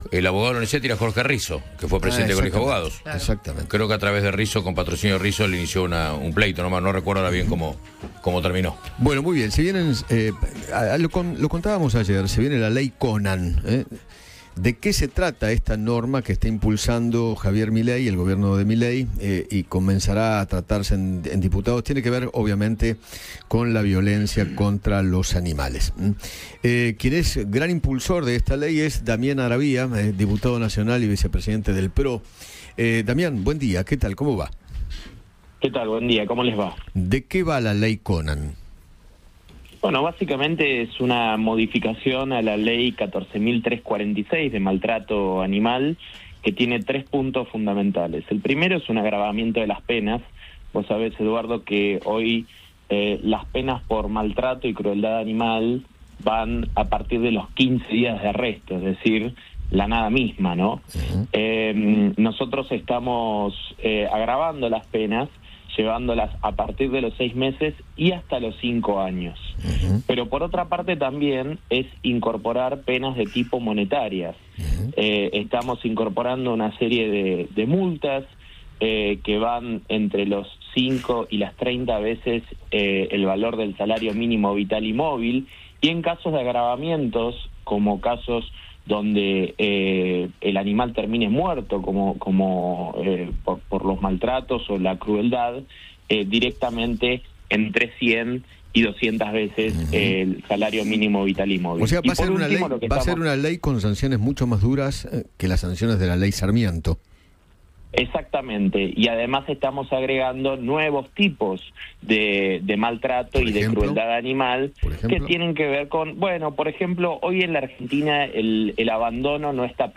El diputado nacional, Damián Arabia, habló con Eduardo Feinmann sobre el proyecto de ley que impulsó contra el maltrato animal y que ya tuvo la aprobación de Javier Milei.